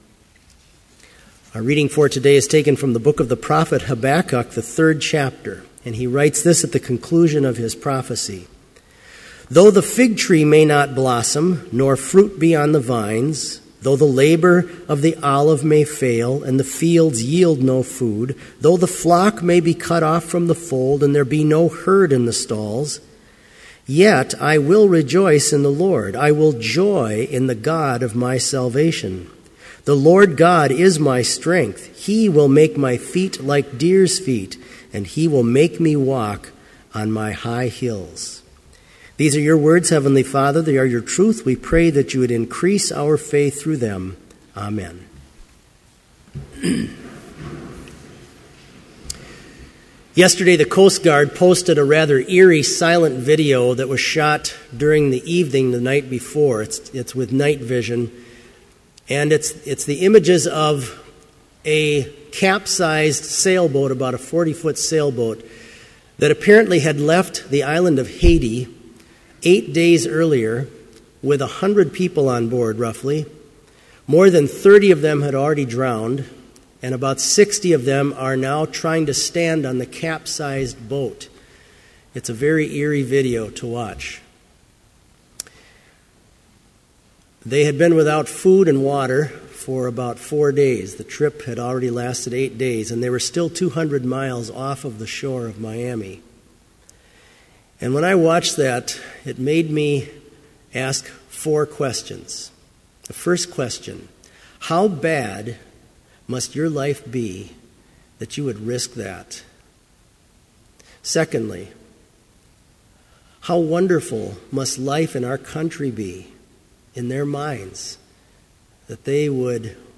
Complete Service
• Homily
This Chapel Service was held in Trinity Chapel at Bethany Lutheran College on Wednesday, November 27, 2013, at 10 a.m. Page and hymn numbers are from the Evangelical Lutheran Hymnary.